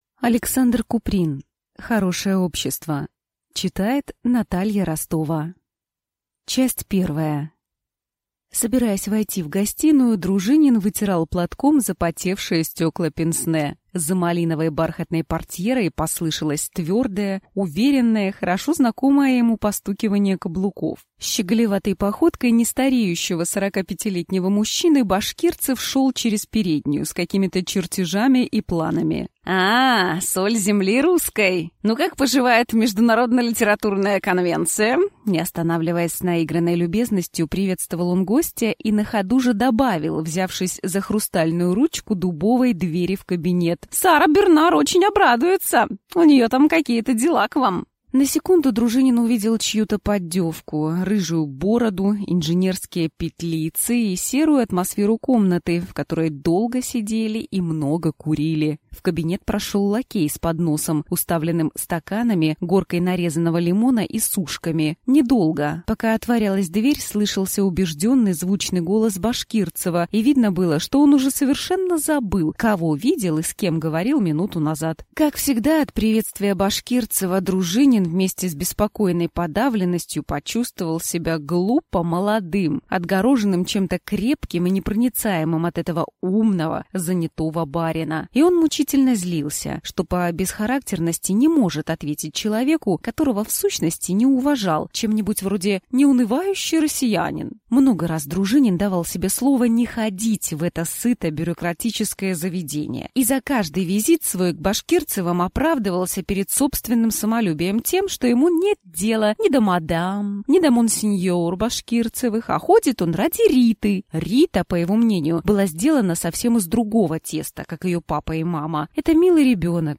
Аудиокнига Хорошее общество | Библиотека аудиокниг